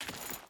Dirt Chain Walk 4.wav